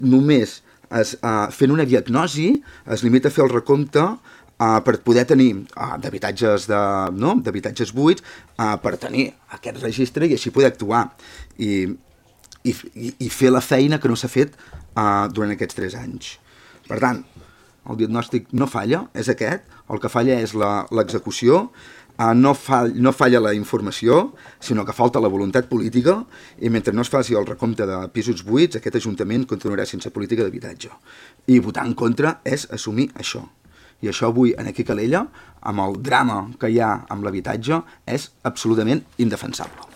El ple municipal de Calella va viure un debat intens sobre la situació de l’habitatge arran d’una moció presentada per la CUP per completar el recompte d’habitatges buits i activar mesures municipals d’intervenció.
Comas va insistir en la manca d’actuacions per part del govern municipal i va atribuir la situació a una falta de voluntat política per abordar el problema.